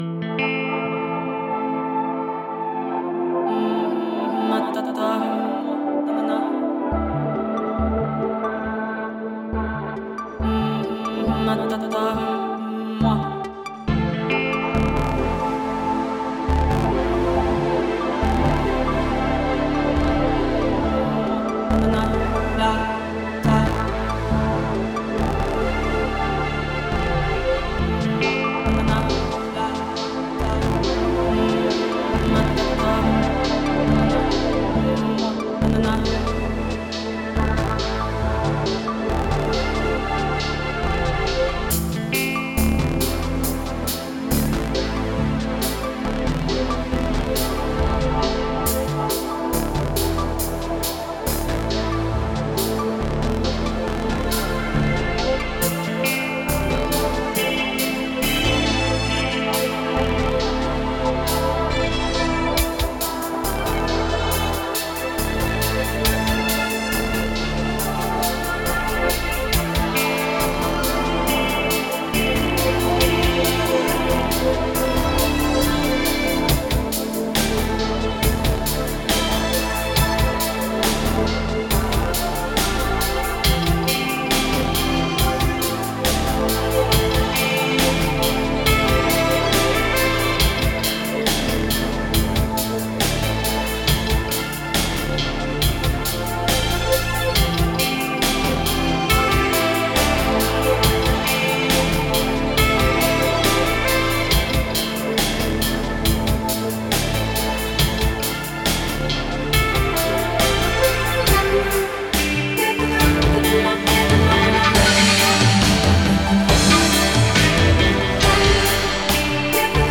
The music bed fits together better. There still may be rework especially the synth pads, which are equally present throughout.
There are a LOT of artificial vocal layers, too many. There are also many sax layers. Just bringing up the drums helped.
138 BPM
Very dense so far.